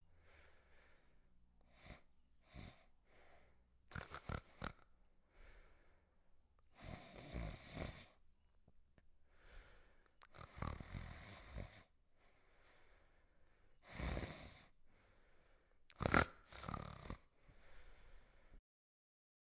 鸟儿的啪啪声 " 鳄鱼打鼾
描述：只是短吻鳄打鼾
Tag: 打鼾 短吻鳄 佛罗里达 珊瑚 山墙 性质 现场录音